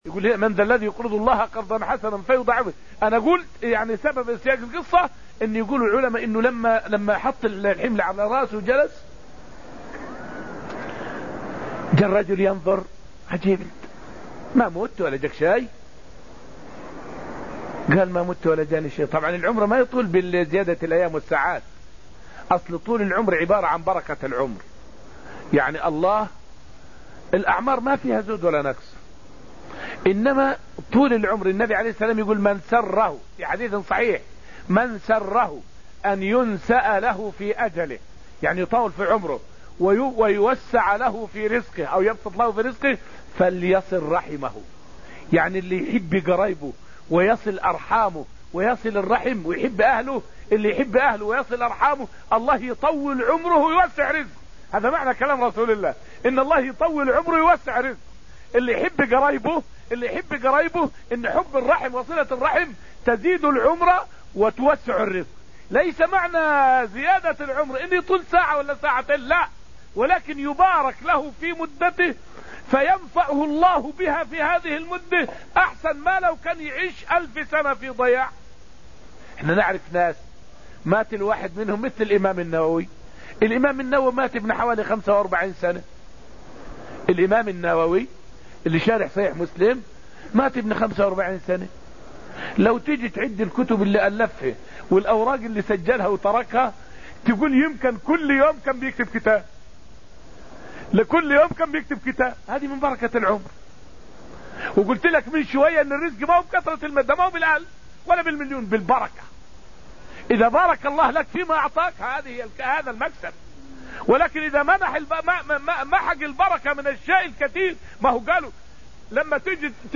فائدة من الدرس الثالث والعشرون من دروس تفسير سورة الحديد والتي ألقيت في المسجد النبوي الشريف حول فطرة الله في الخلق وعجائب صنعه فيهم.